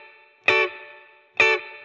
DD_TeleChop_130-Dmaj.wav